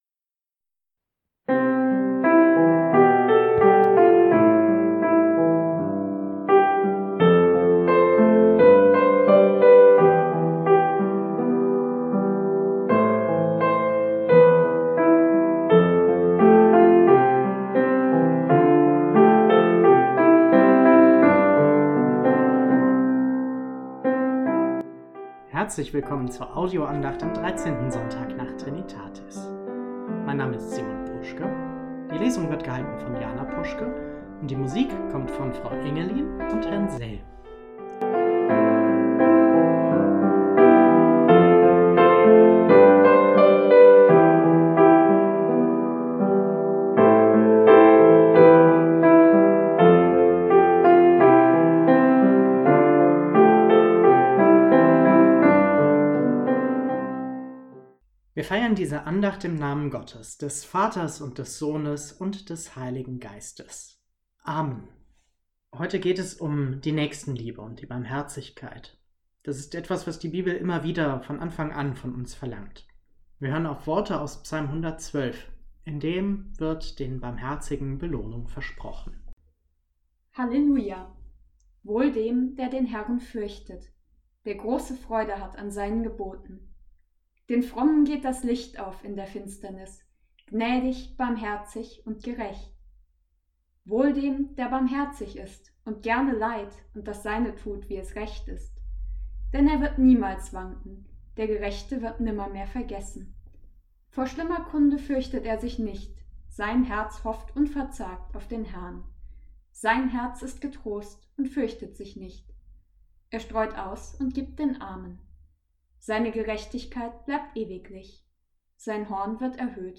Gottesdienst am Sonntag 06.09.2020 - Neues
Bei tollem Sonnenschein haben wir uns wieder auf den Vorplätzen der Gemeindehäuser versammelt.
Die Predigt ist hier auch wieder als Audio-Version verfügbar.